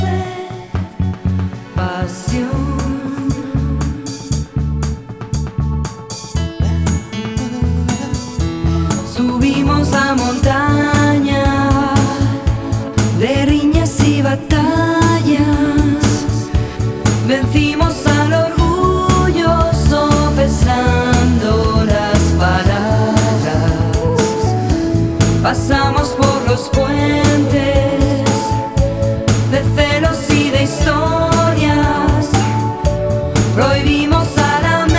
Contemporary Latin